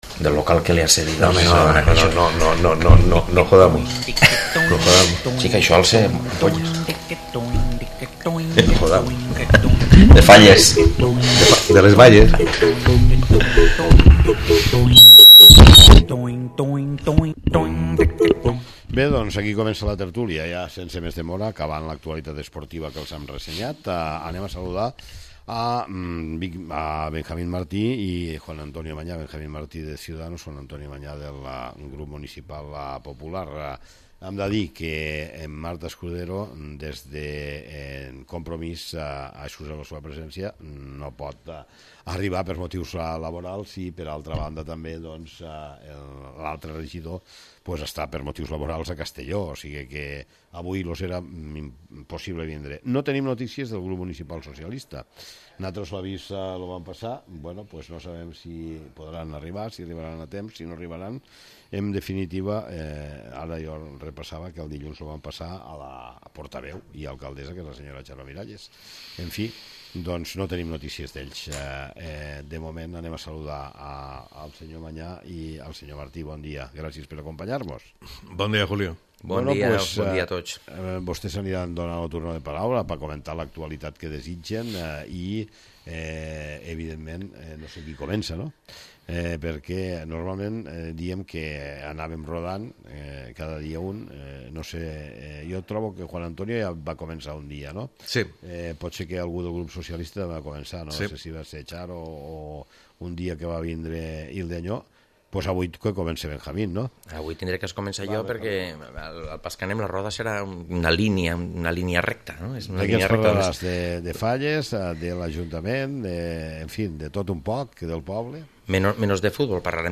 La tertúlia política: Benicarló 24-03-23
Han participat Juan antonio Mañá del grup Popular i Benjamín Martí del grup Ciudadanos